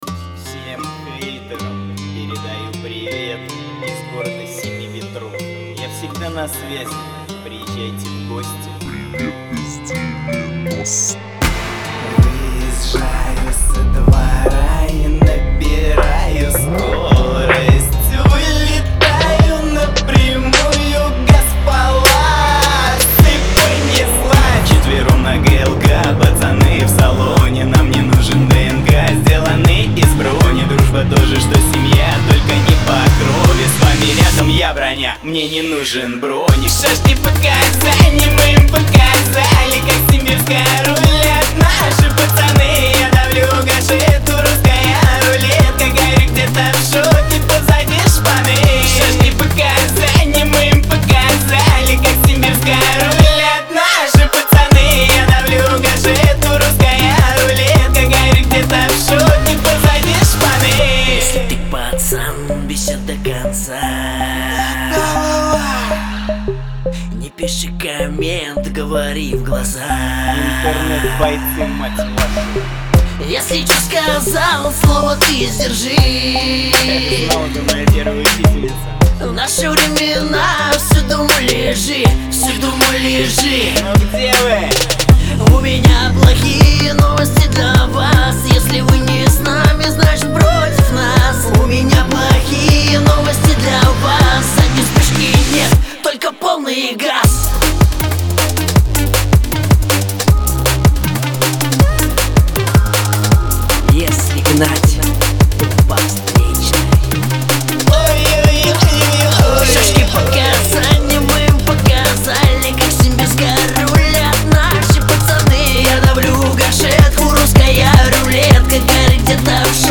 это энергичная композиция в жанре хип-хоп